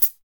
FUNKY PDL.wav